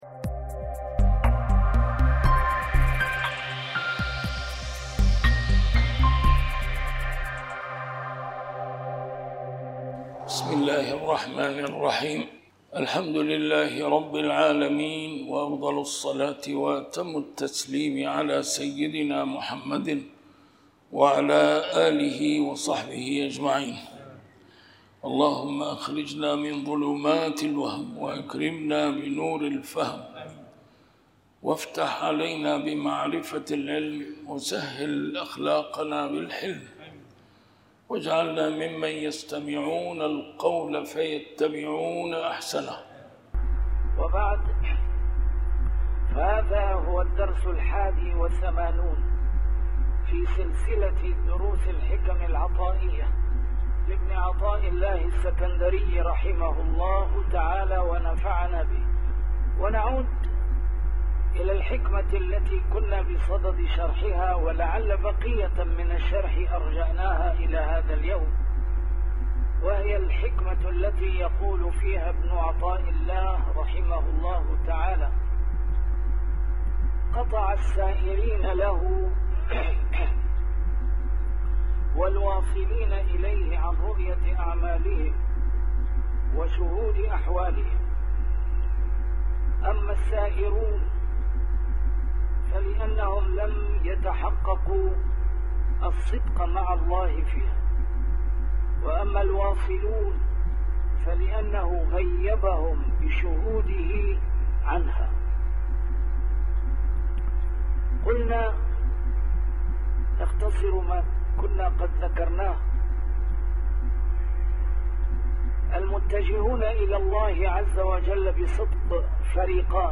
A MARTYR SCHOLAR: IMAM MUHAMMAD SAEED RAMADAN AL-BOUTI - الدروس العلمية - شرح الحكم العطائية - الدرس رقم 81 شرح الحكمة 59